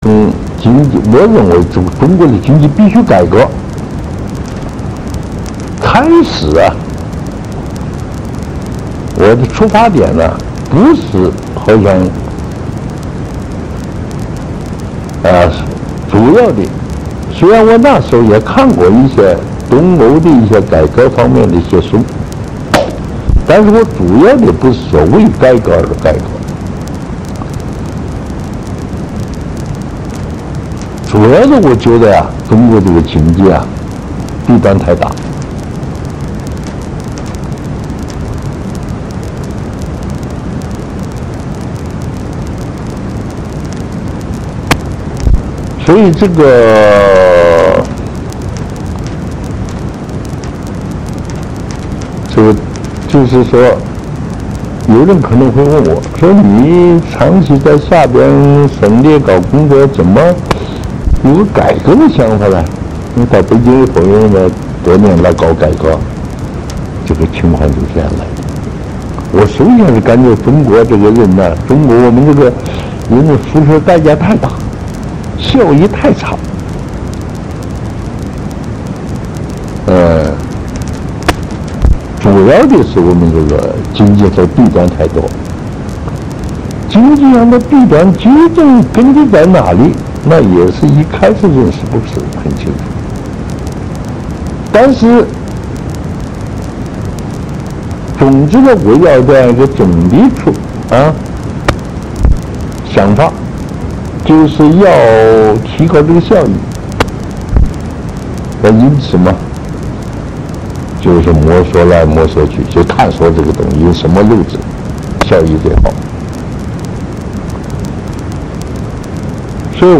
赵紫阳录音回忆录《改革历程》节选之四